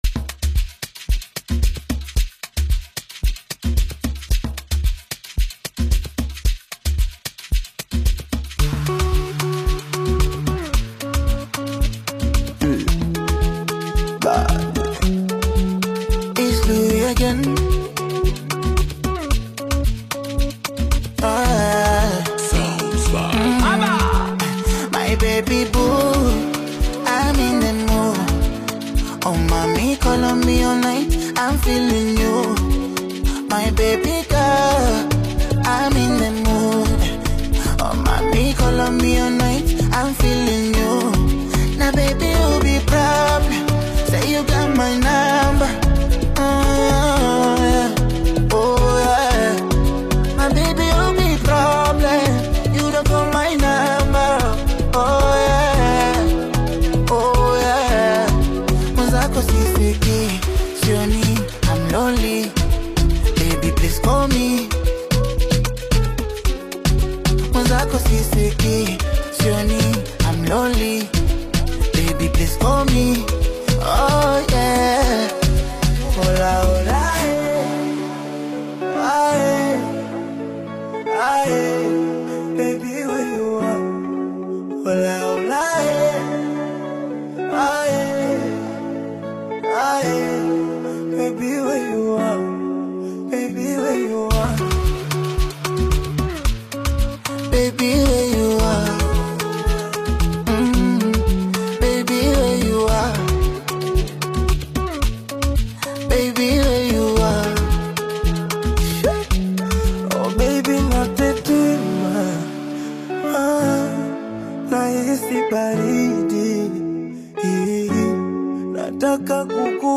Tanzanian bongo flava recording artist